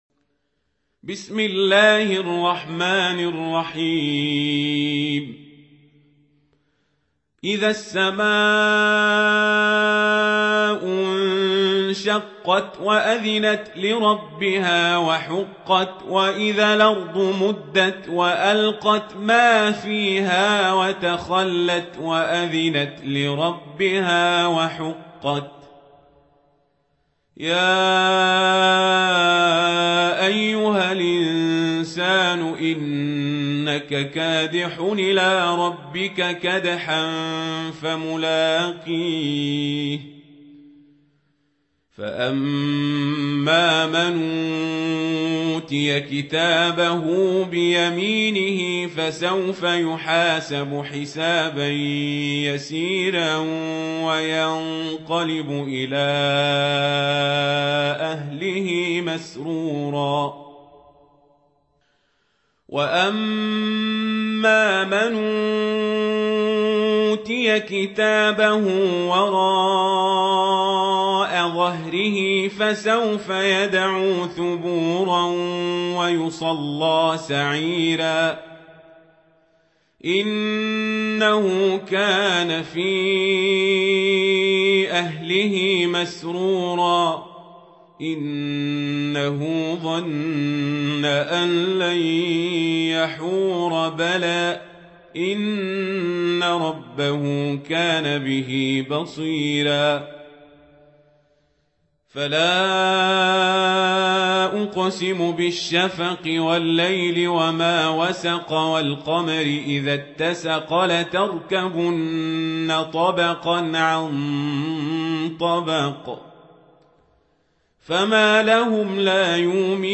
سورة الانشقاق | القارئ عمر القزابري